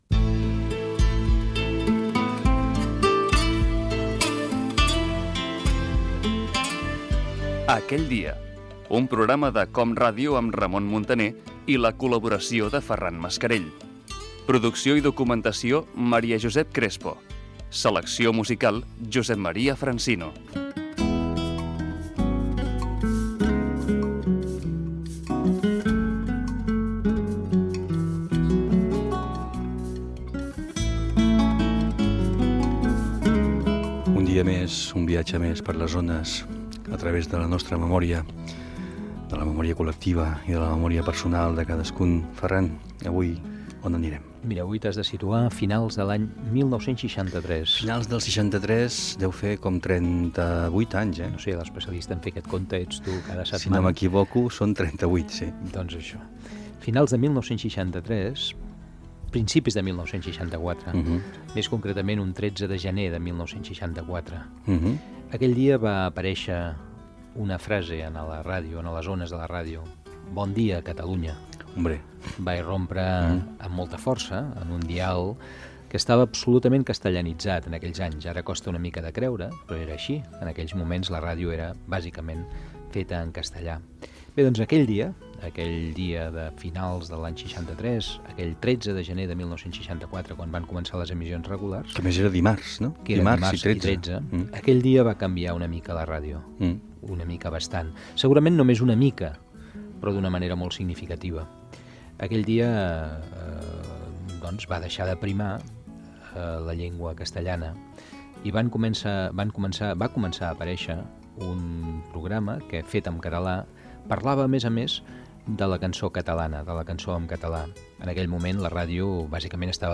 Careta del programa i entrevista al radiofonista Salvador Escamilla, recordant el 13 de febrer de 1964 quan naixia el programa "Radio-Scope"